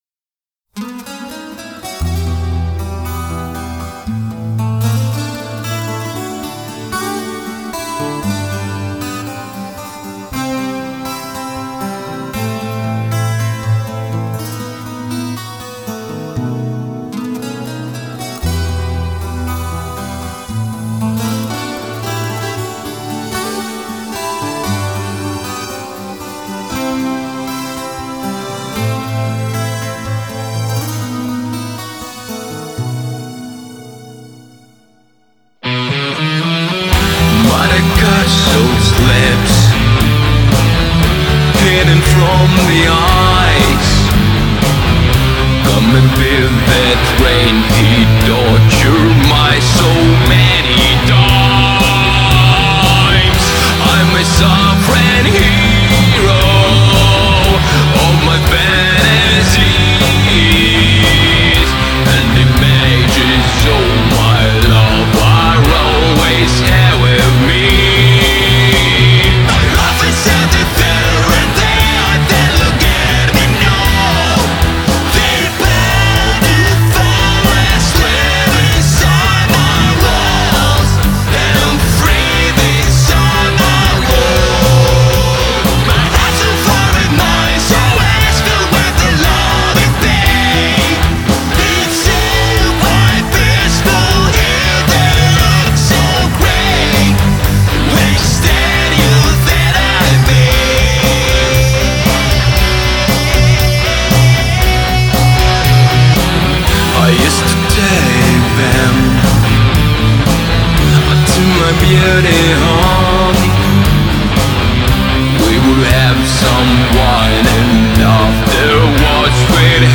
Музыкальное творчество